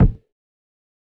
Kick (3).wav